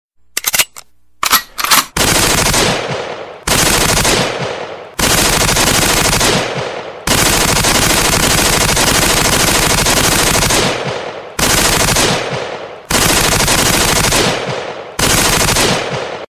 vystrely-iz-avtomata_24582.mp3